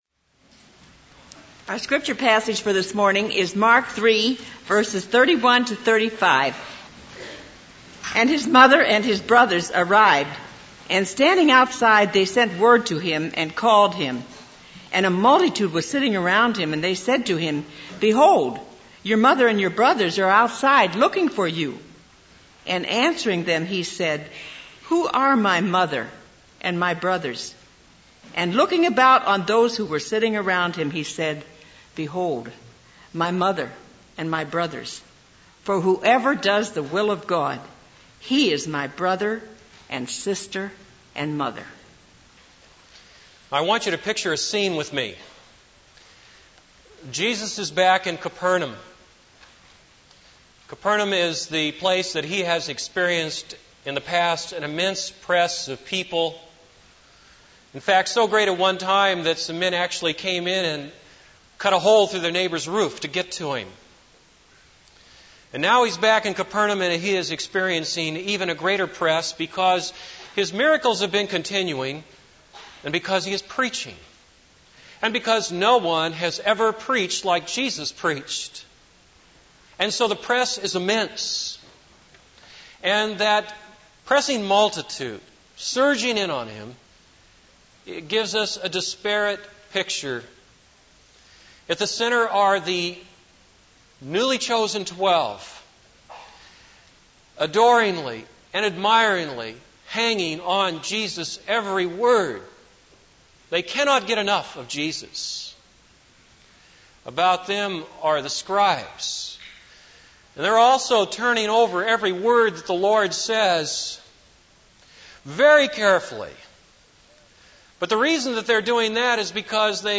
This is a sermon on Mark 3:31-35.